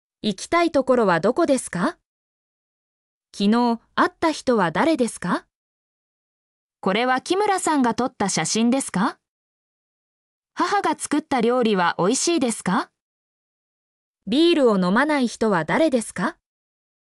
mp3-output-ttsfreedotcom-22_XgNqiM0R.mp3